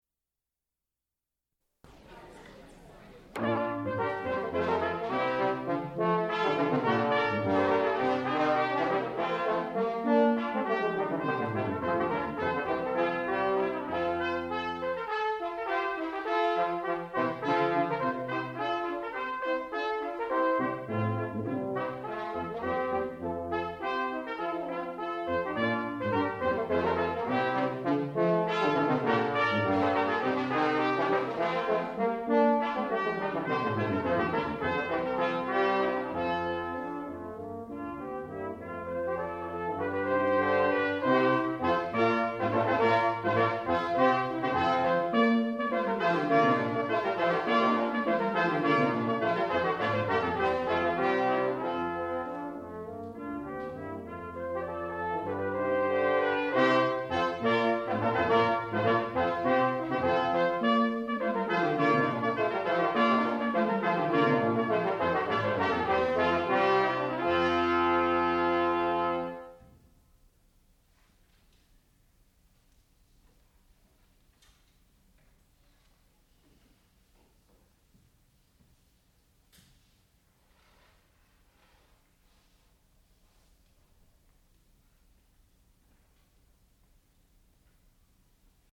sound recording-musical
classical music
The Shepherd Brass Quintet (performer).